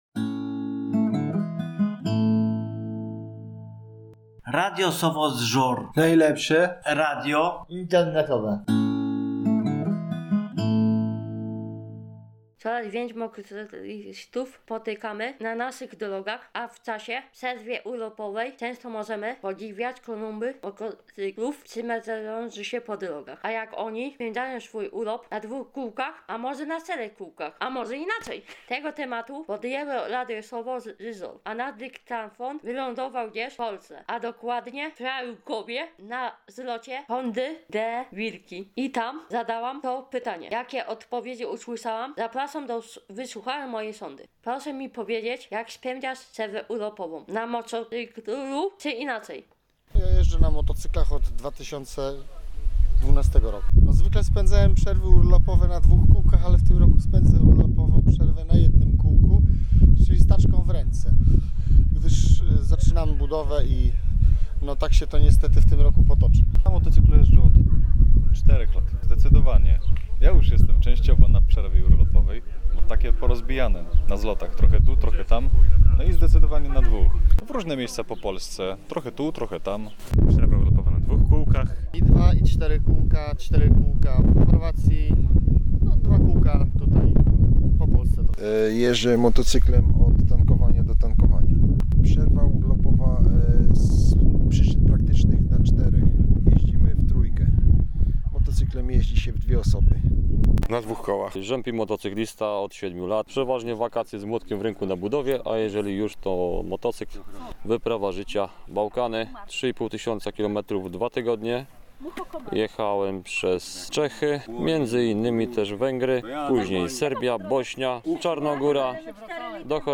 A nasze macki powędrowały do Fijałkowa w powiecie Przasnyskim na Zlot Sympatyków Hondy NTV, Deauville.